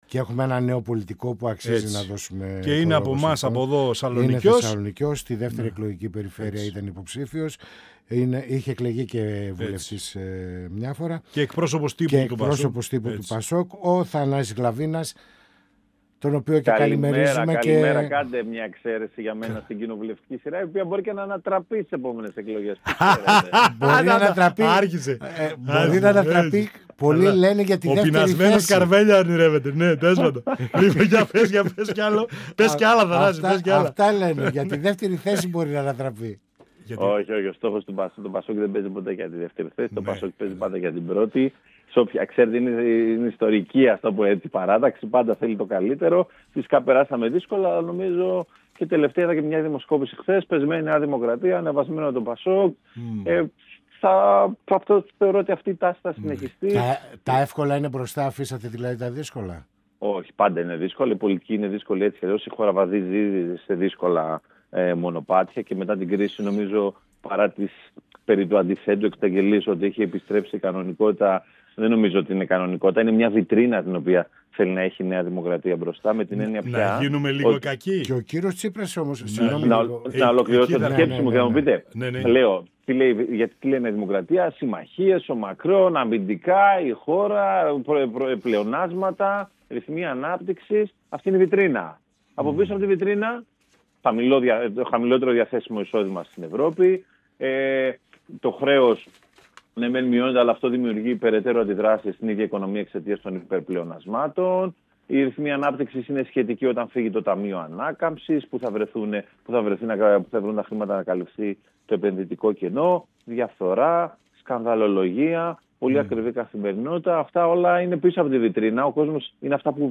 Στις προτεραιότητες του ΠΑΣΟΚ μετά το Συνέδριό του και ενόψη των επερχόμενων βουλευτικών εκλογών, είτε γίνουν πρόωρα, είτε στην ολοκλήρωση της τετραετίας την Άνοιξη του 2027 αναφέρθηκε το Μέλος του Πολιτικού Συμβουλίου του ΠΑΣΟΚ-ΚΙΝΑΛ Θανάσης Γλαβίνας , μιλώντας στην εκπομπή «Πανόραμα Επικαιρότητας» του 102FM της ΕΡΤ3.
Πανοραμα Επικαιροτητας Συνεντεύξεις